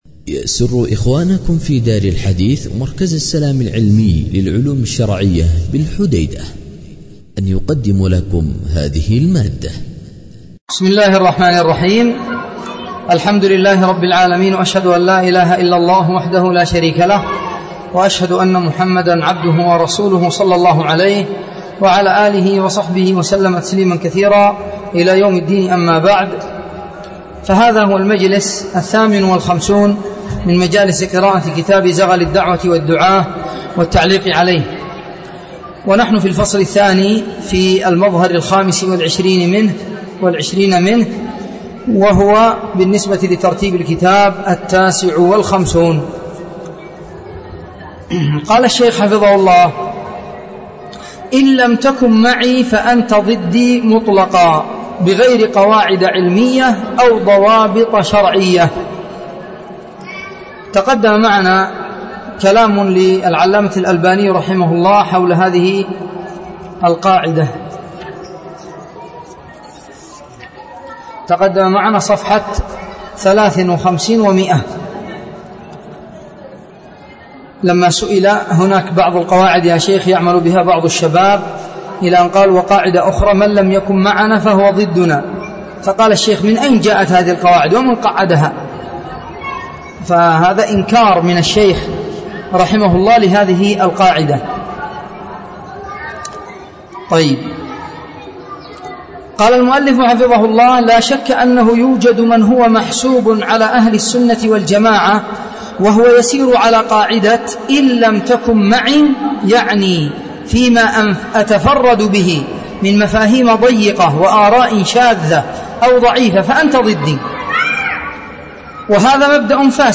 ٥٩ – إن لم تكن معي فأنت ضدي مطلقا بغير قواعد علمية أو ضوابط شرعية ٦٠ – عدم ضبط وفهم أنواع الخلاف شرح